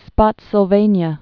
(spŏtsəl-vānyə)